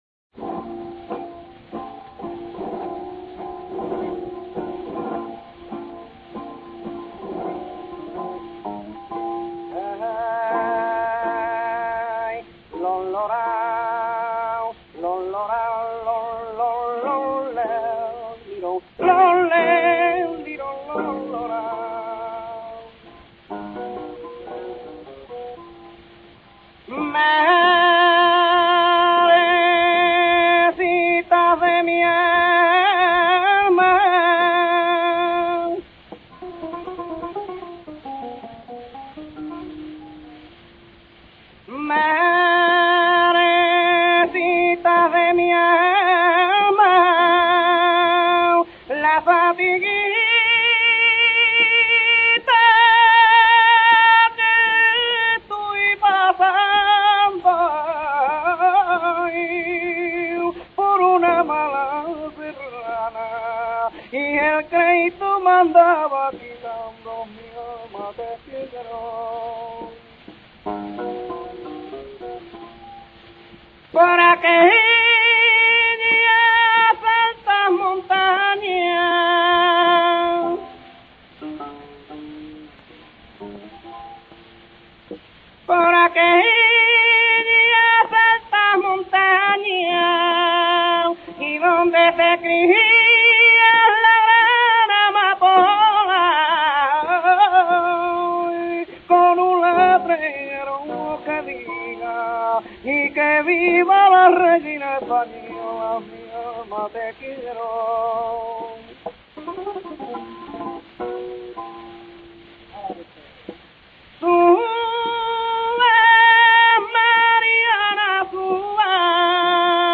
Cante folkl�rico aflamencado, hoy casi desaparecido, con copia larga de versos de distintas medidas que concluye con un estribillo. Su cadencia y tono melodioso parecen mostrar cierta vinculaci�n con el cancionero popular andaluz, aunque posteriormente los gitanos trashumantes lo adaptasen al tono expresivo de su existencia errante. Musicalmente, en su versi�n flamenca, presenta afinidad con los tientos, con un comp�s mon�tono y una cierta resonancia oriental.